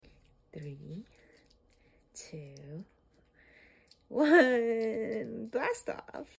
the laugh at the end sound effects free download